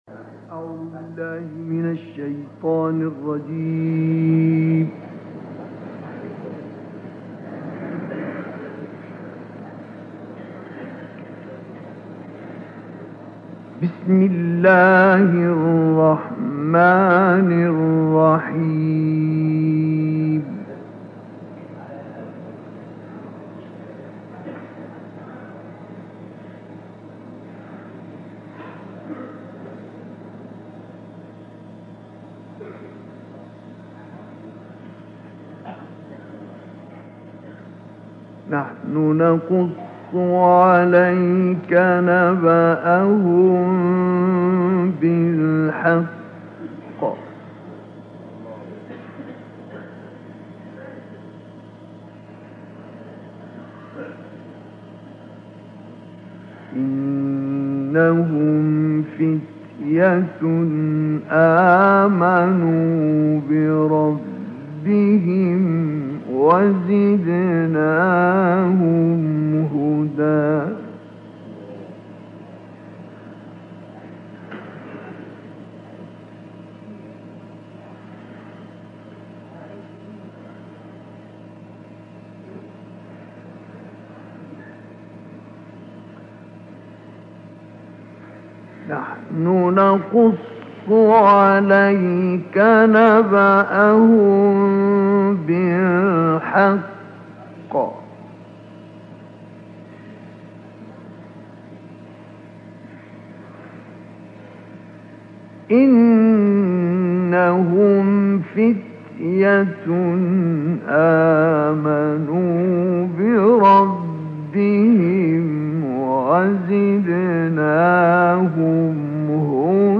الكهف وقصار السور.. القارئ الكبير الشيخ مصطفى اسماعيل
تلاوات معطرة - الكوثر: تلاوة رائعة من تلاوات الشيخ مصطفى اسماعيل من سور الكهف والضحى والشرح والتين والعلق عام 1965.